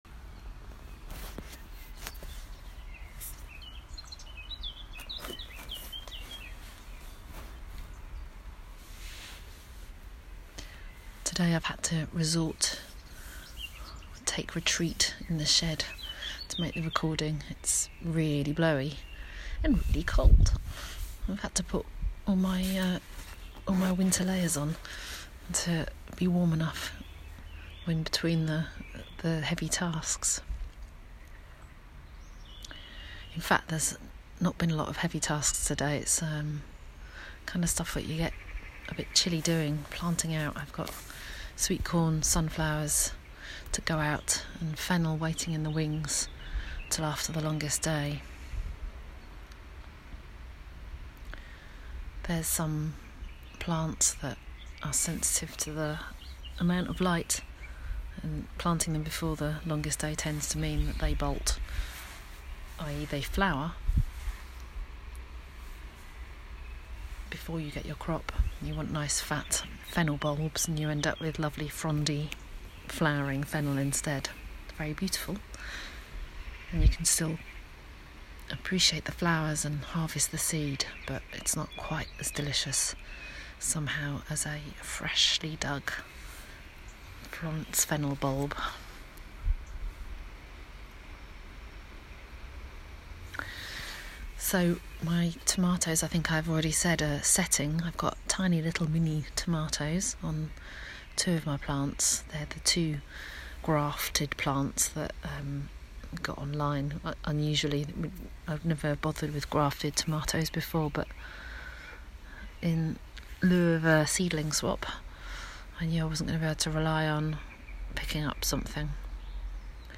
Hear from one woman and her dog as she explores the organic gardening world in short sound bites.
*Please note, outside recording carried out in accordance with national guidance as part of daily exercise with respect to social distancing*